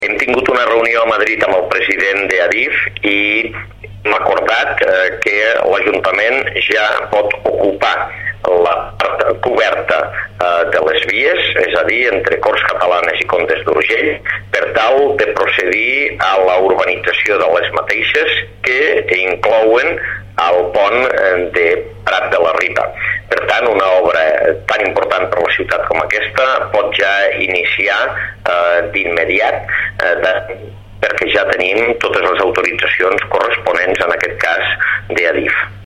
S'adjunta tall de veu Amb aquest document, ADIF autoritza la Paeria amb una concessió administrativa per ocupar la llosa de formigó que cobreix el tram comprès entre entre Corts Catalanes i Comtes d'Urgell.
tall-de-veu-angel-ros